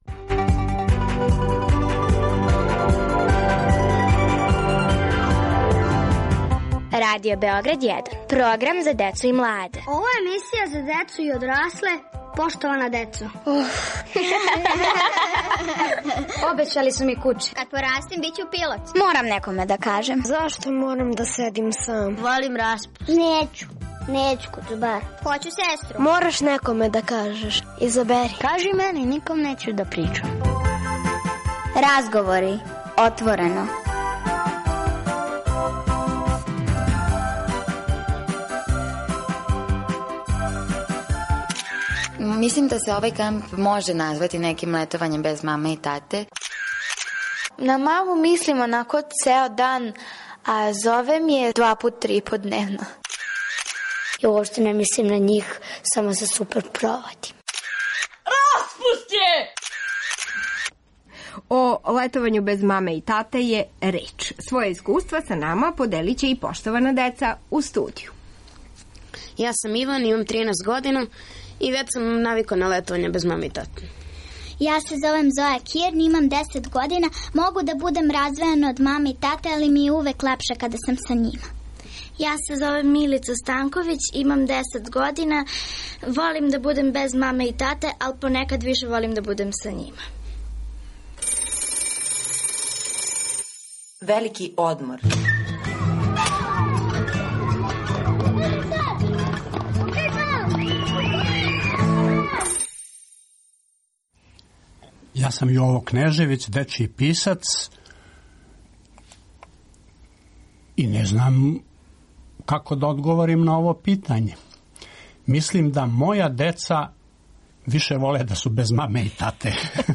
Разговори - отворено: Поштована деца ћаскају о летовању без маме и тате.